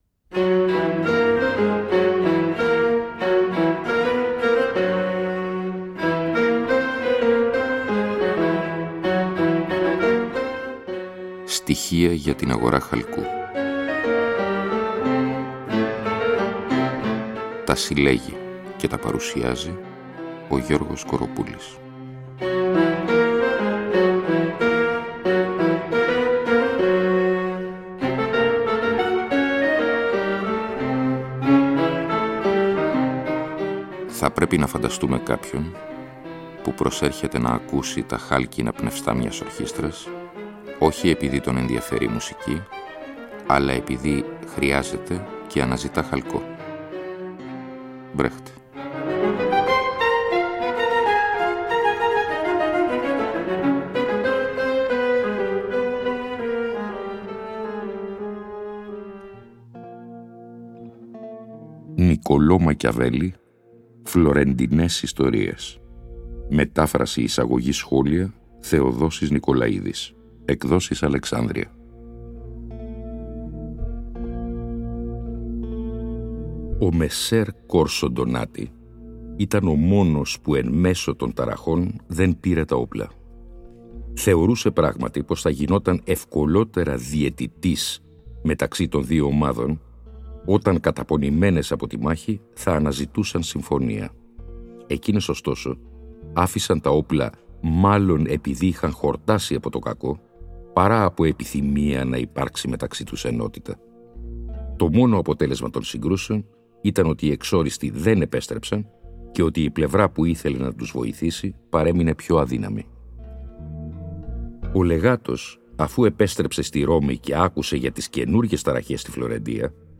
Εκπομπή λόγου.